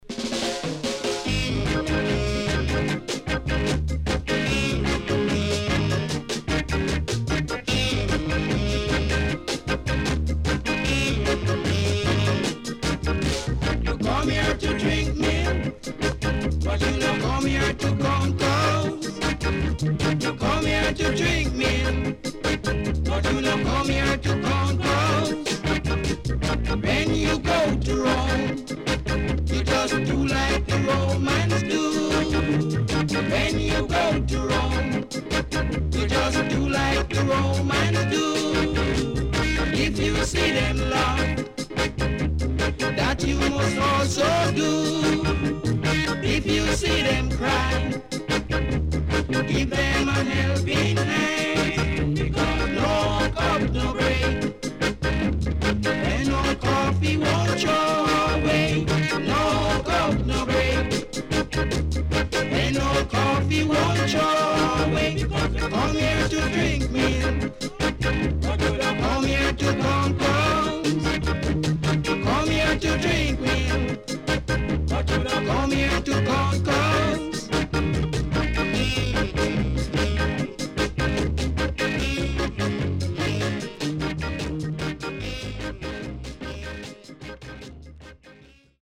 CONDITION SIDE A:VG(OK)〜VG+
SIDE A:うすいこまかい傷ありますがノイズあまり目立ちません。